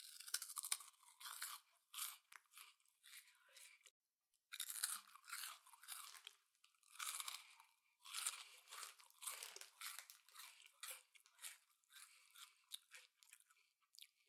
兔子吃东西
描述：一只兔子吃新鲜脆脆蔬菜的声音。
Tag: 格格 脆脆的 兔子吃